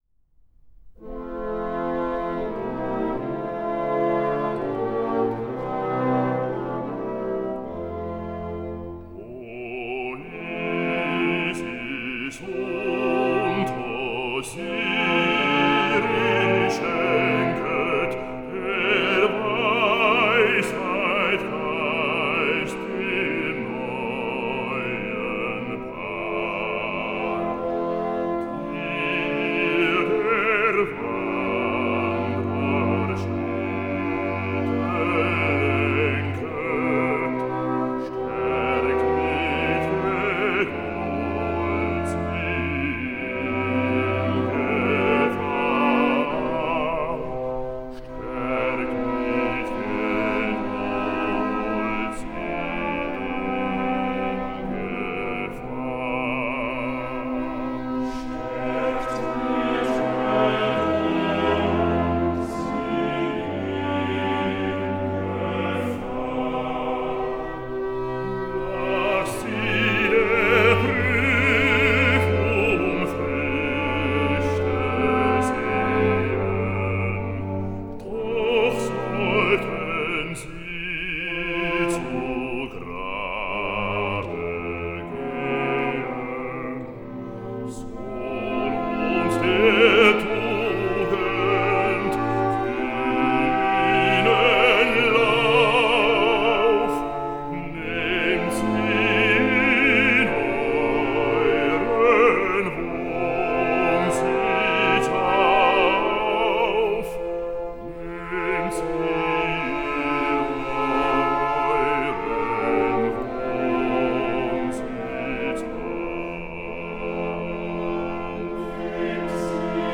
Música clásica
Canto